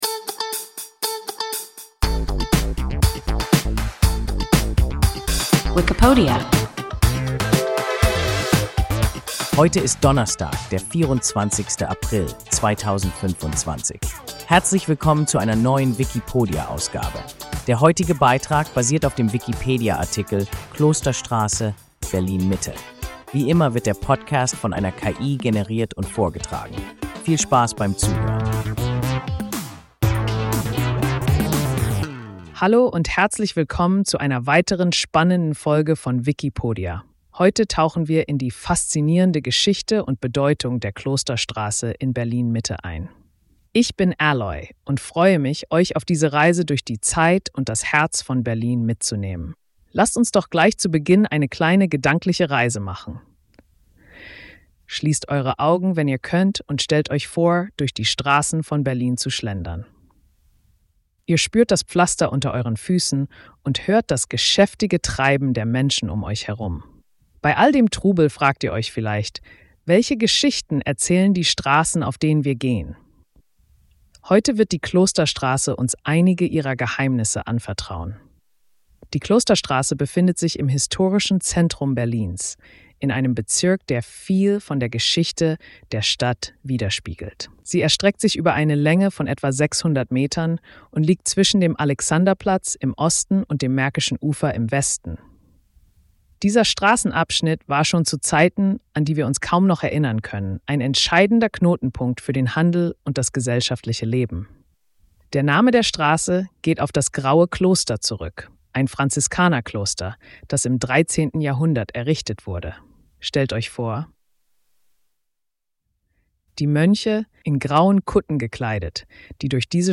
Klosterstraße (Berlin-Mitte) – WIKIPODIA – ein KI Podcast